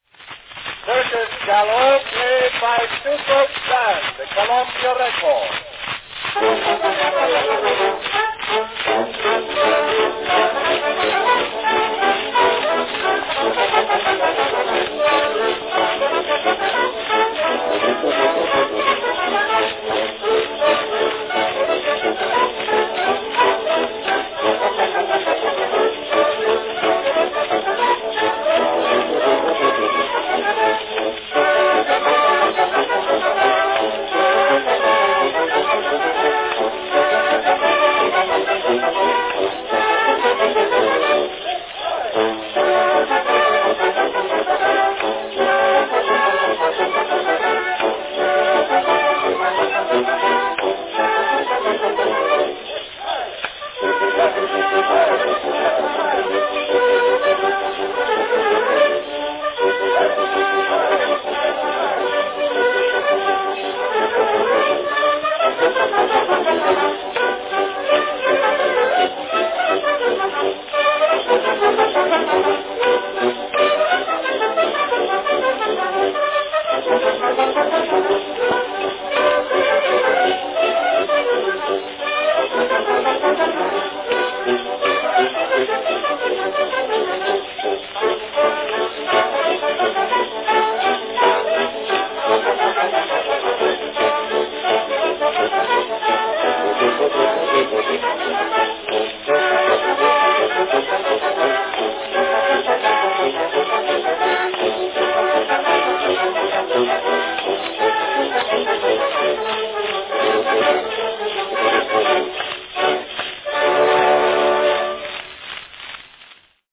From 1904, a fast-paced number, Circus Gallop, played by John Philip Sousa's Band.
Category Band (descriptive)
Performed by Sousa's Band
Announcement "Circus Gallop, played by Sousa's Band.   Columbia record."
Enjoy this snappy little piece complete with descriptive circus shouts of "sieg heil!" (hail victory!) – a common exclamation co-opted decades later by the Nazi's.